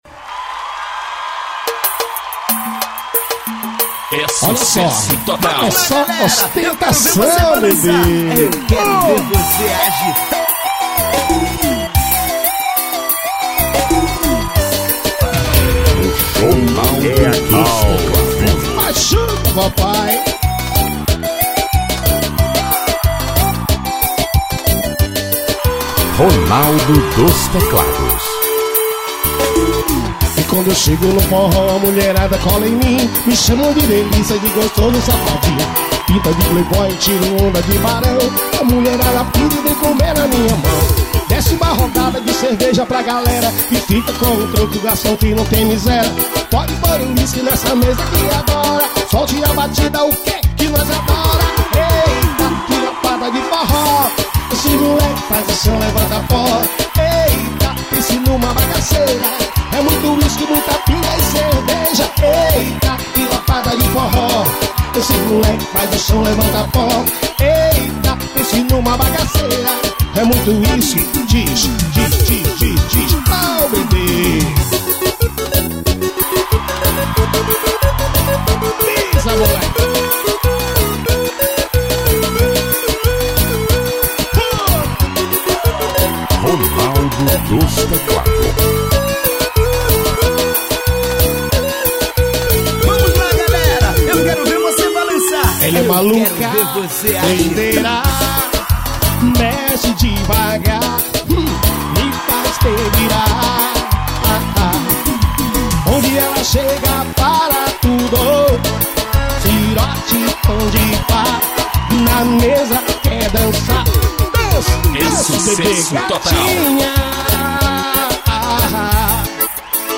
SET DANCE AO VIVO.